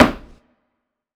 010_Lo-Fi Stomp Perc.wav